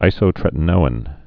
(īsō-trĕtn-oin)